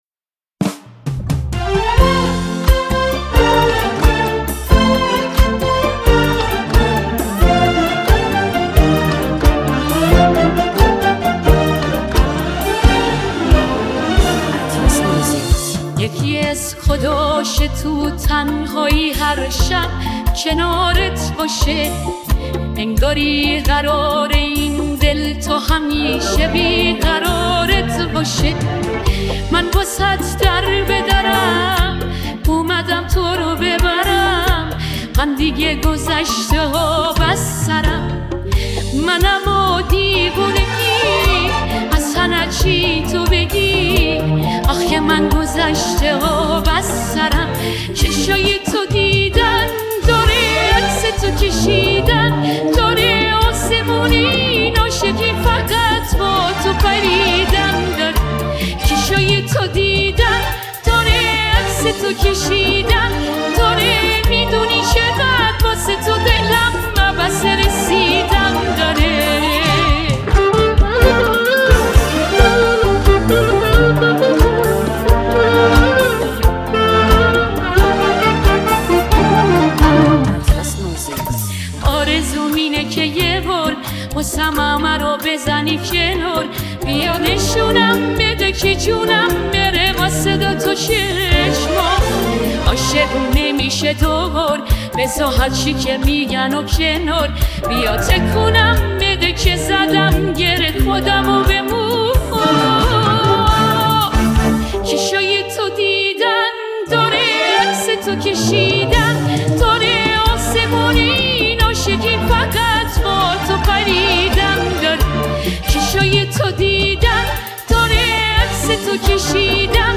ریمیکس هوش مصنوعی
ریمیکس بیس دار